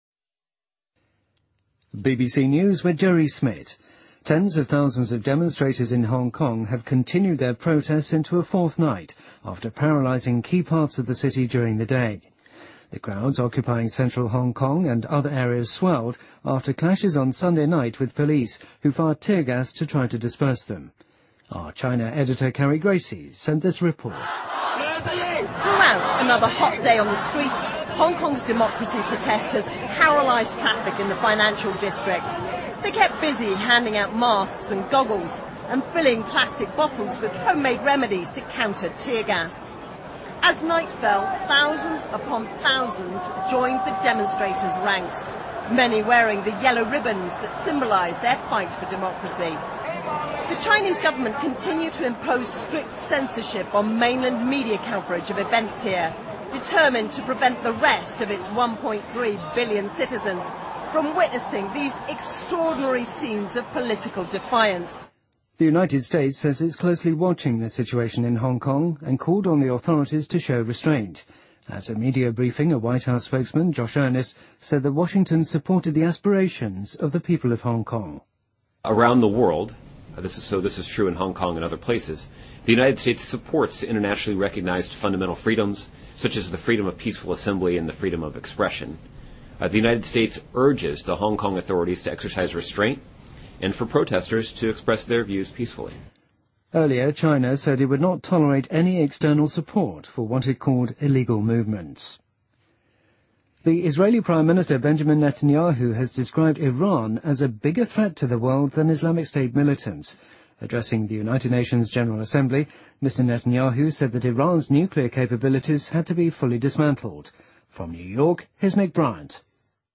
BBC news,西班牙宪法法庭暂停原定于11月在加泰罗尼亚举行的独立公投